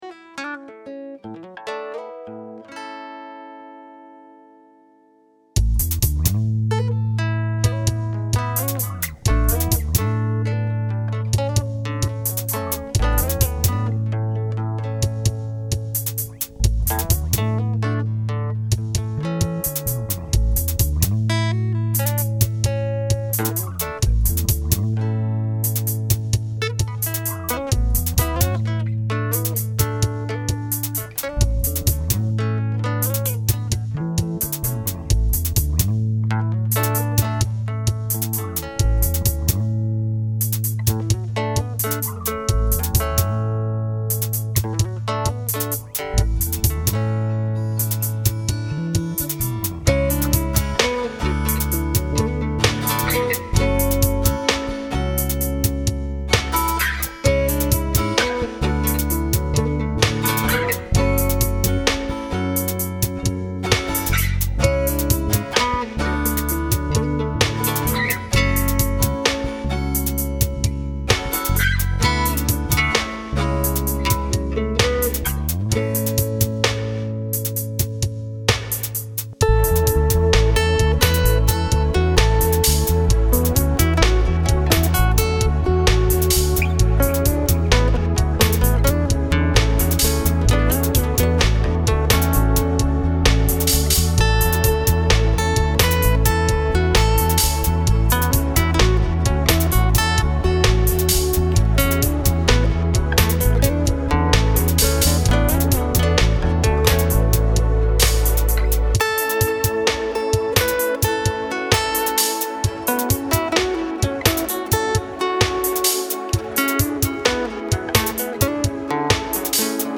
All instruments are live and original. Drums are made by us through logic. It is in the key of A with the lead guitar riff being in the A Dorian scale. All guitar parts are on an acoustic plugged straight into the desk. Effects on guitars include delay and reverb and the bass has been left un-touched.
There are two of us in the group and we are looking for comments and suggestions of any kind.